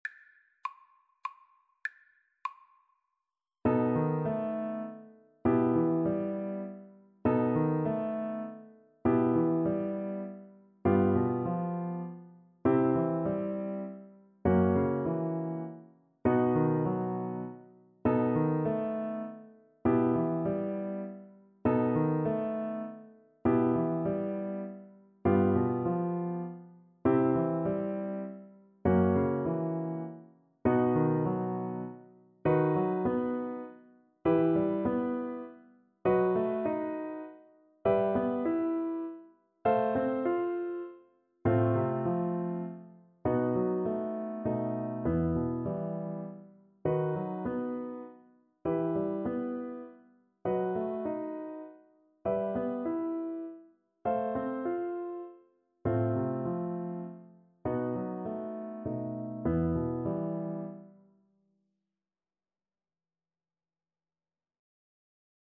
3/4 (View more 3/4 Music)
Etwas bewegt
Classical (View more Classical Violin Music)